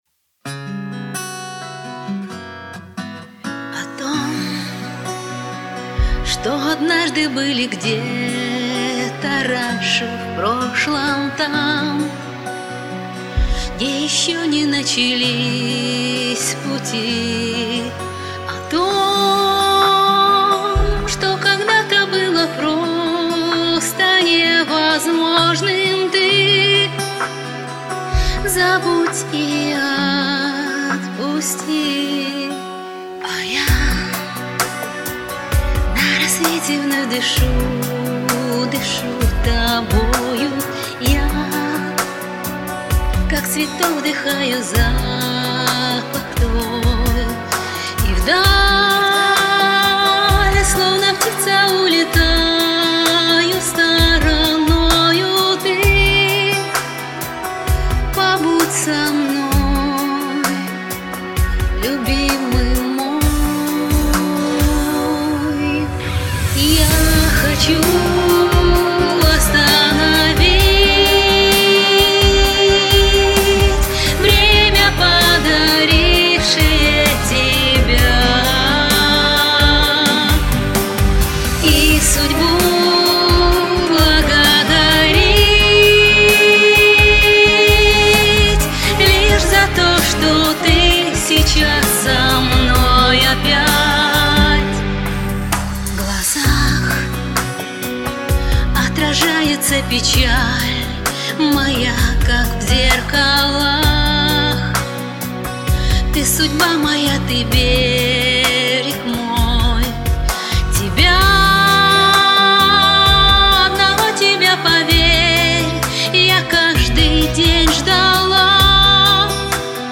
Настолько подача обалденная!!!!!!!!!